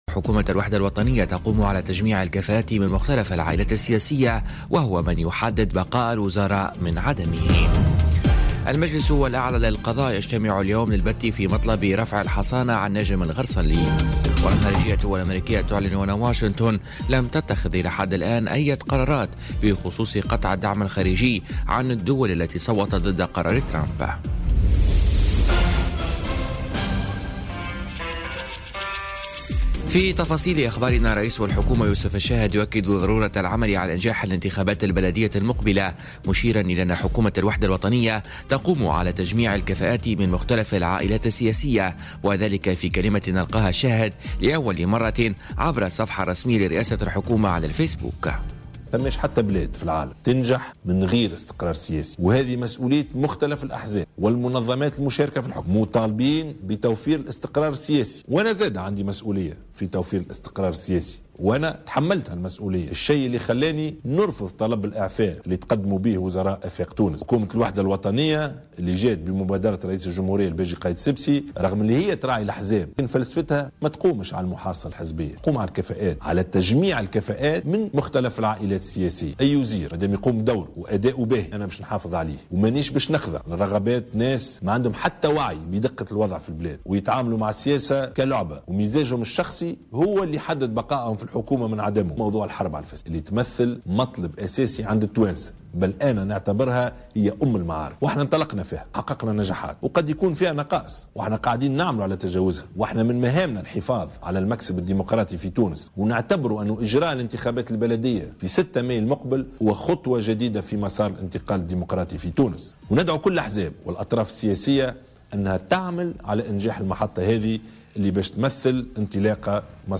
نشرة أخبار السابعة صباحا ليوم الجمعة 22 ديسمبر 2017